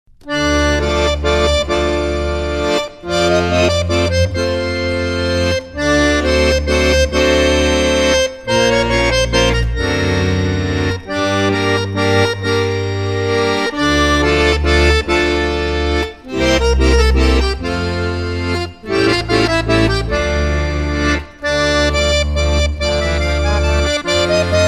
Accordionist